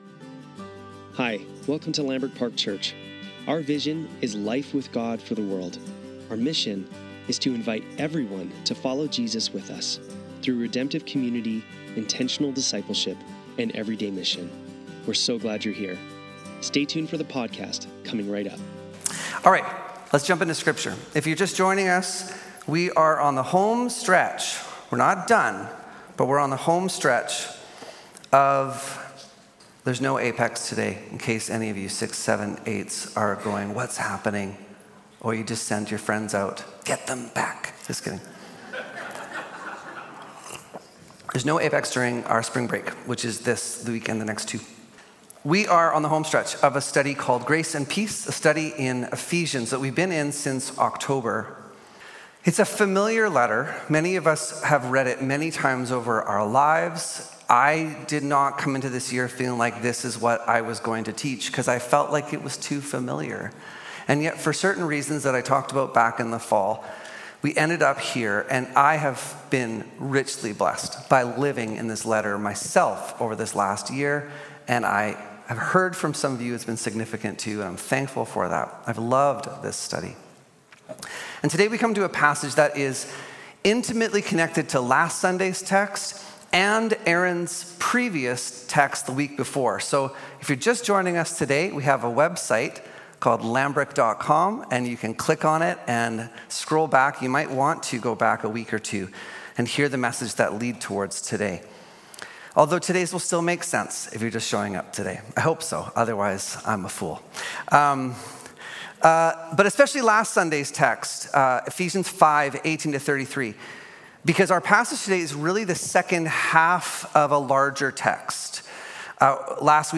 Sunday Service - March 16, 2025